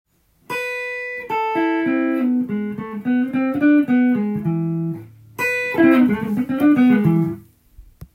このフレーズもAメロディックマイナースケールが
使われいて、定番的なおしゃれフレーズです。